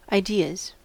Ääntäminen
Ääntäminen US : IPA : [aɪ.ˈdi.əz] Tuntematon aksentti: IPA : /aɪ.ˈdɪəz/ Haettu sana löytyi näillä lähdekielillä: englanti Ideas on sanan idea monikko.